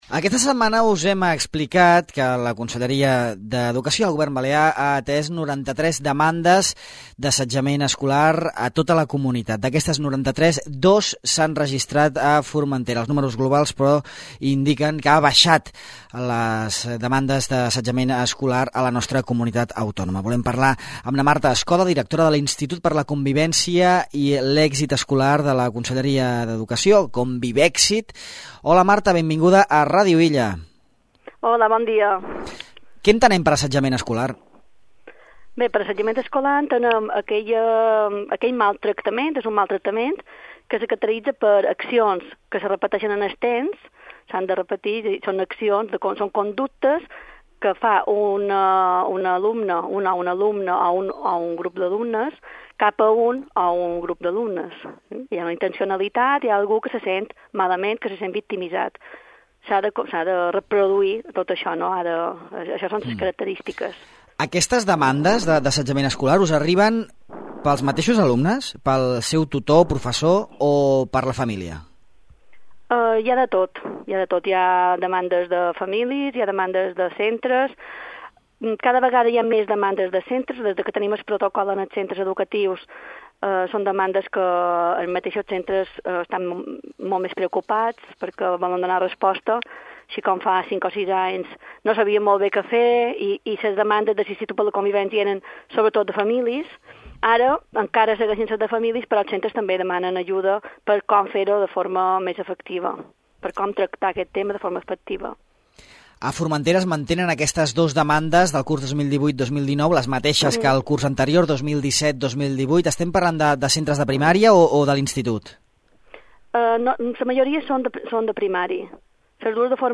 Al de Far a Far en parlem amb Marta Escoda, Directora de l’Institut per a la Convivència i l’Èxit Escolar de la Conselleria balear d’Educació.